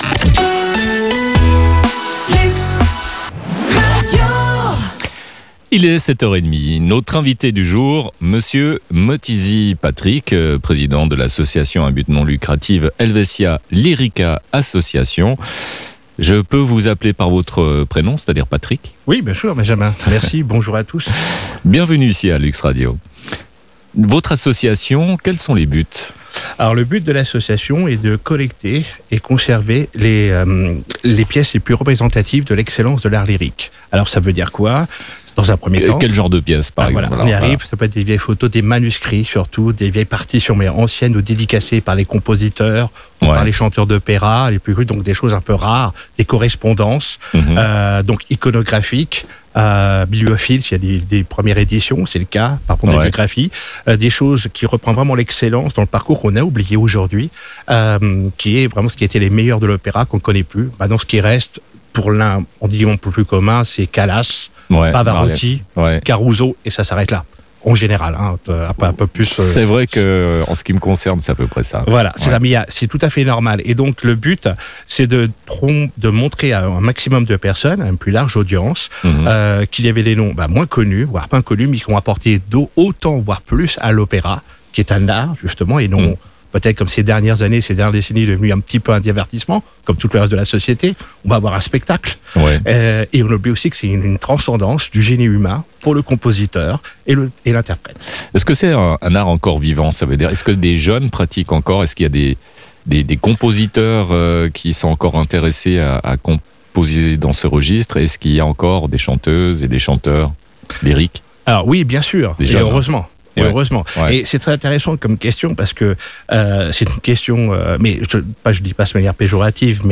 Helvetia Lyrica | Interview